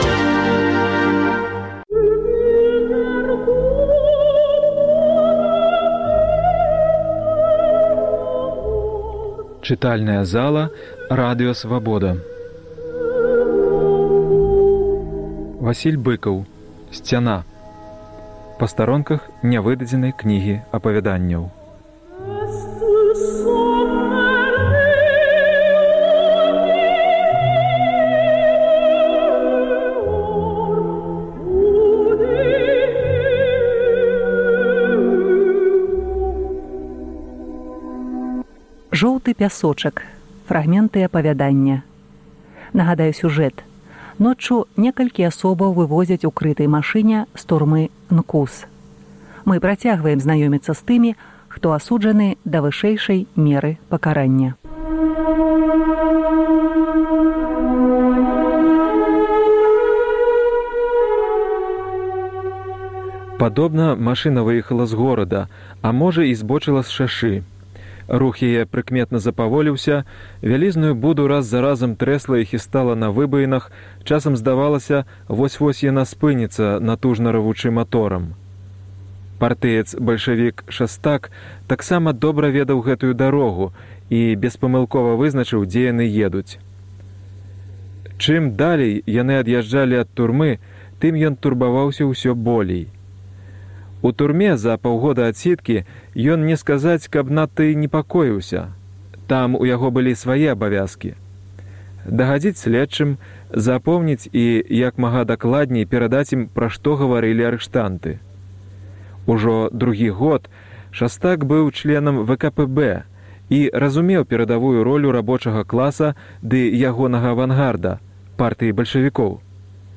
Проза Быкава ня ўпісвалася ў ідэалягічны кантэкст. Васіль Уладзімеравіч перадаў рукапіс ў Прагу, і супрацоўнікі Свабоды чыталі ягоную прозу. А для музычнвана афармленьня мы выбралі Песьню Сольвейг Эдварда Грыга.